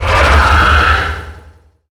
Sfx_creature_shadowleviathan_chitter_05.ogg